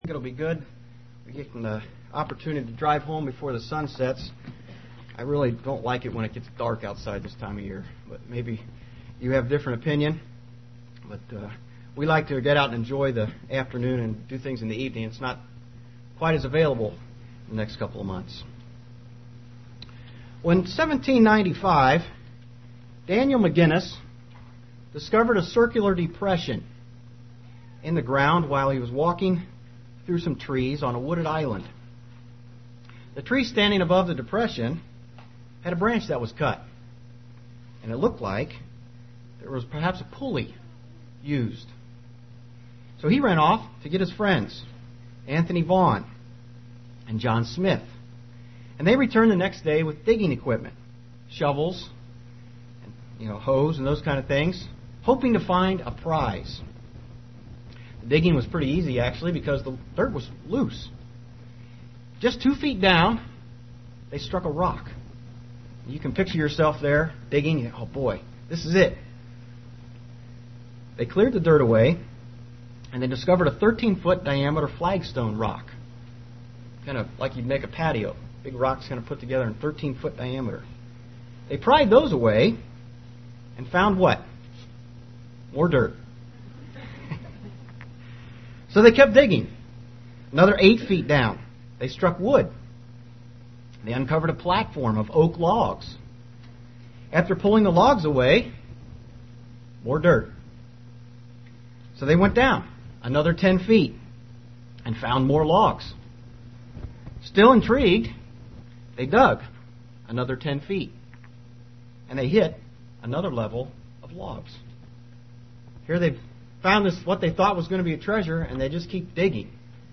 UCG Sermon Notes Notes: Oak Hill has treasure that is impossible to attain.